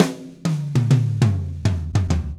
Drumset Fill 05.wav